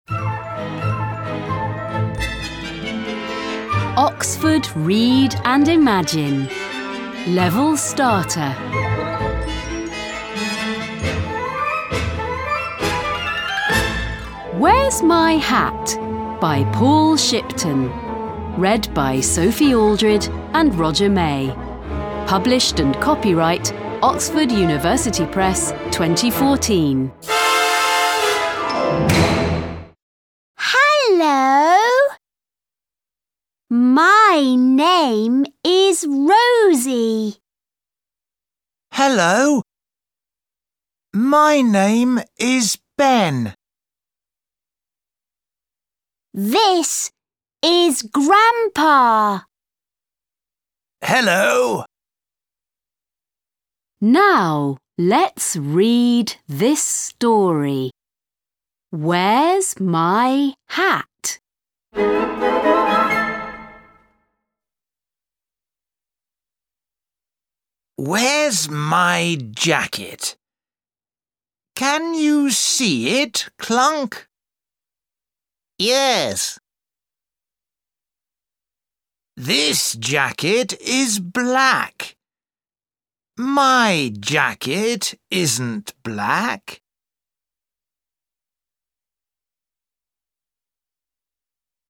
Track 1 Where's My Hat British English.mp3